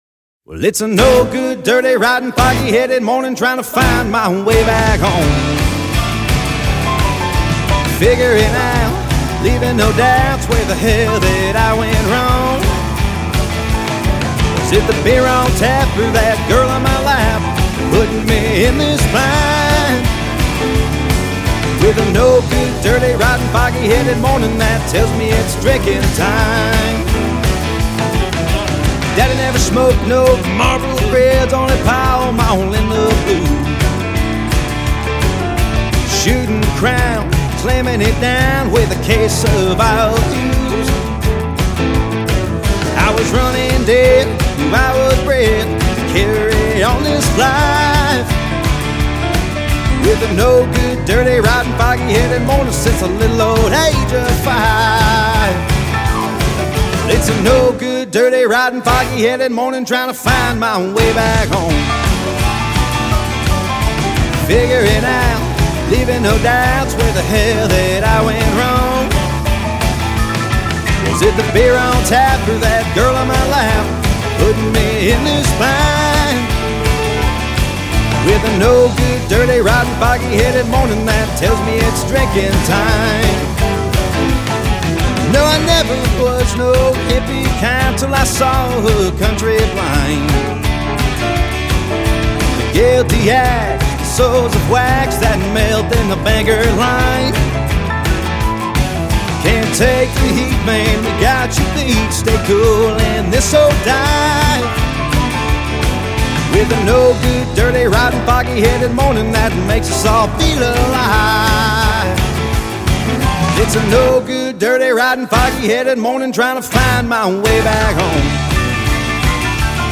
Genre Country